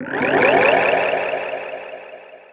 cardget.wav